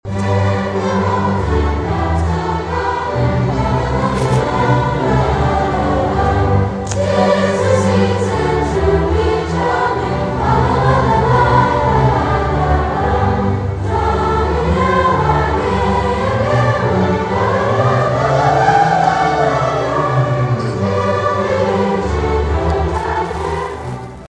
This was the 87th Seasonal Celebration for the district as Emporia High and Emporia Middle School students provided vocal and instrumental holiday music from around the world both Wednesday and Thursday nights.